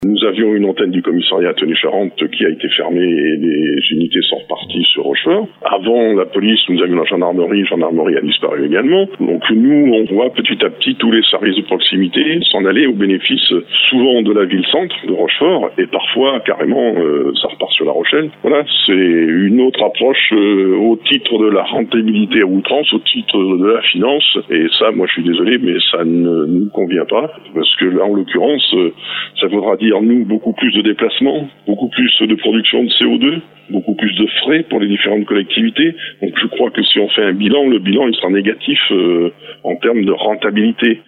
Pour le maire de Tonnay-Charente Eric Authiat, c’est encore un service public de proximité de plus sacrifié sur l’autel de l’économie.